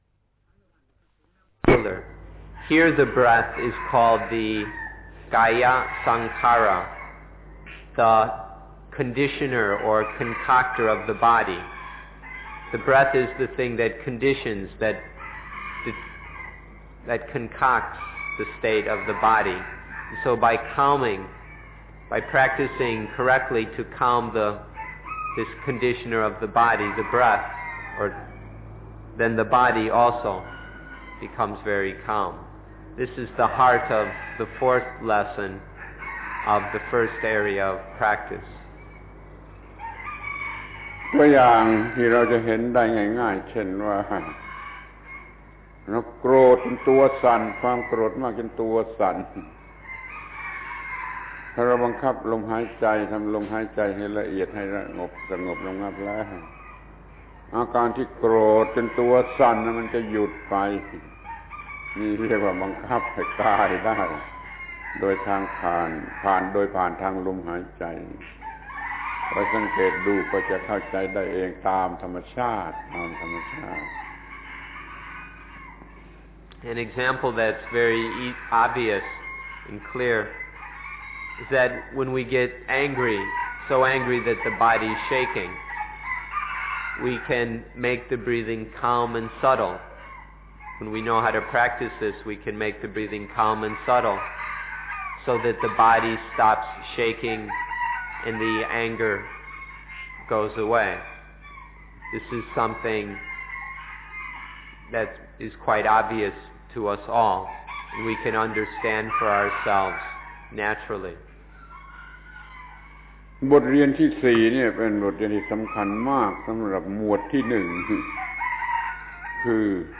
พระธรรมโกศาจารย์ (พุทธทาสภิกขุ) - อบรมพระภิกษุสามเณรชาวต่างประเทศ จากวัดป่านานาชาติโดยท่าน สุเมโธ นำ ครั้ง ๓ อานาปานสติกับพุทธศาสนา หมวดที่ ๑ ,๒ , ๓ ต่อ